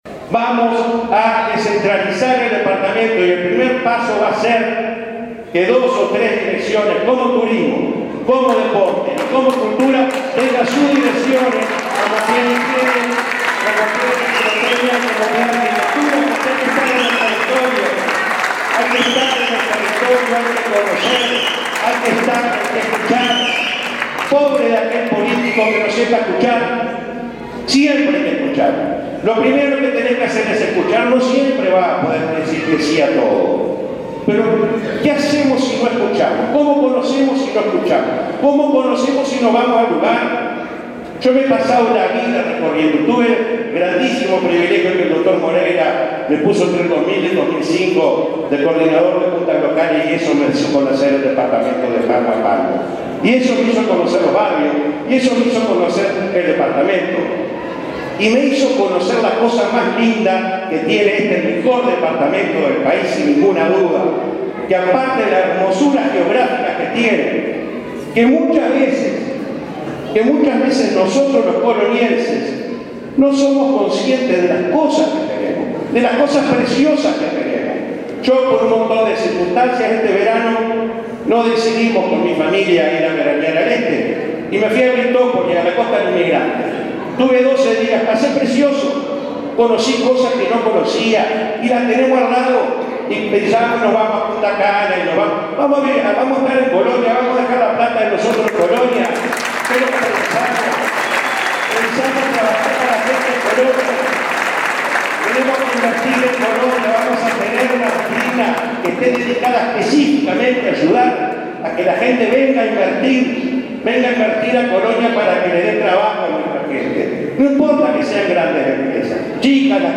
anunció en un acto realizado en Carmelo